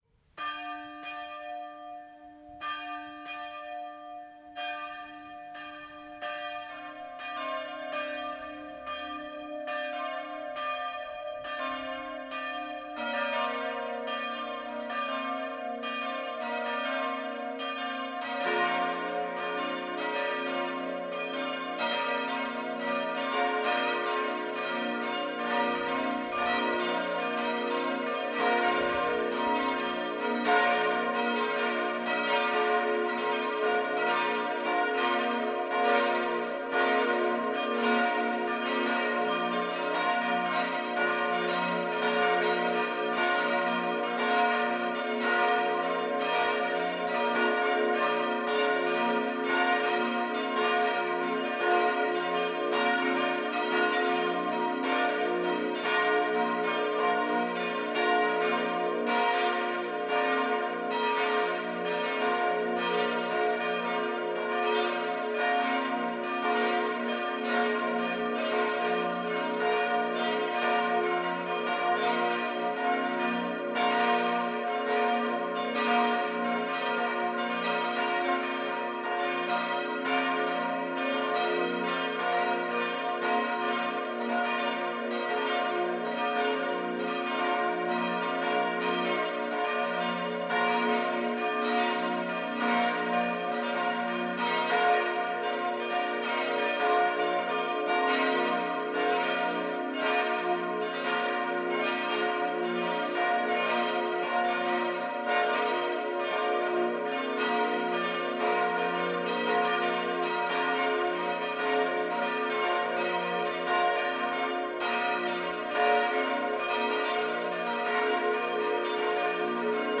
hier ist unsere Kirche: Glockengeläut
Versöhnugskirchenglocken lang.mp3